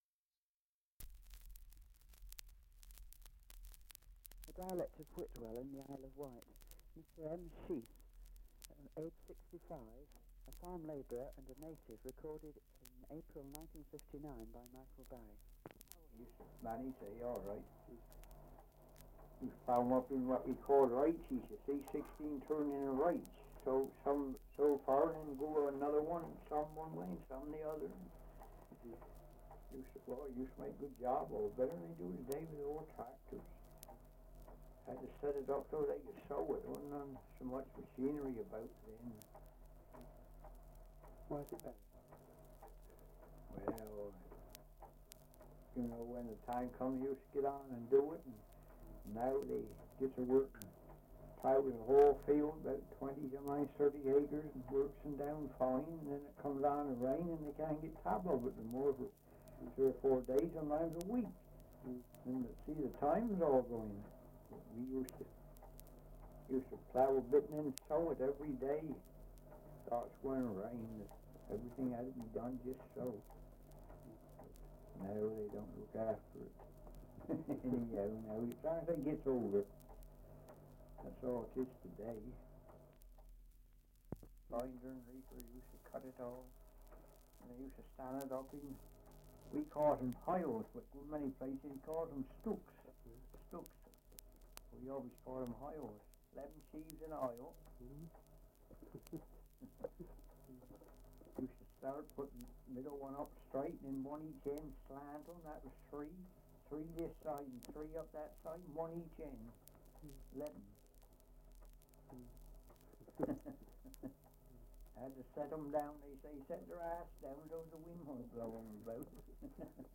Survey of English Dialects recording in Whitwell, Isle of Wight
78 r.p.m., cellulose nitrate on aluminium